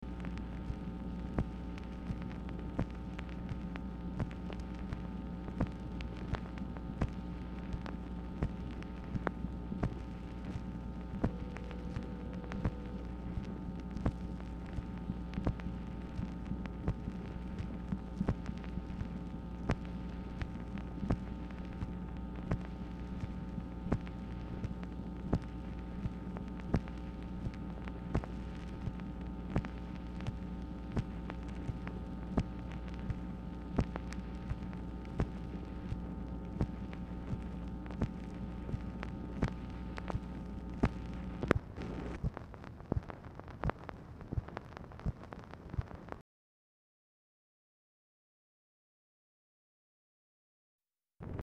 Telephone conversation # 10828, sound recording, MACHINE NOISE, 9/22/1966, time unknown | Discover LBJ
Format Dictation belt